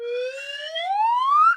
reply.ogg